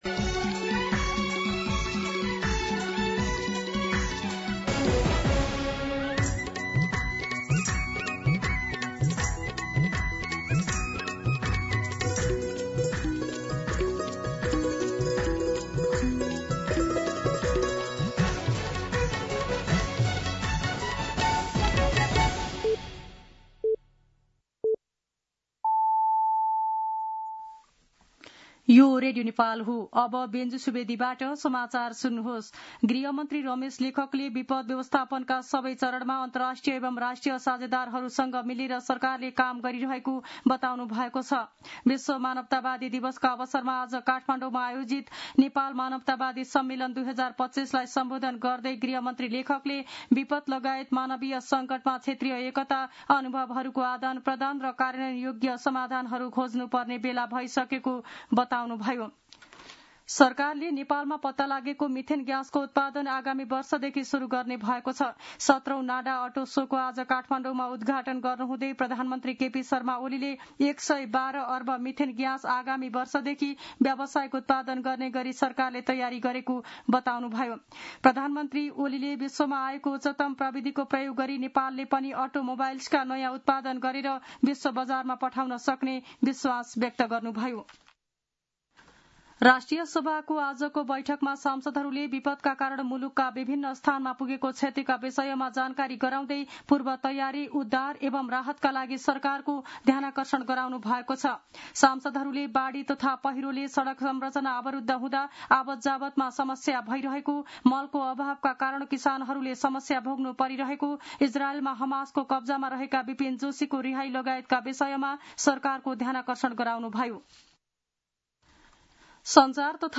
दिउँसो ४ बजेको नेपाली समाचार : ३ भदौ , २०८२
4pm-News-03.mp3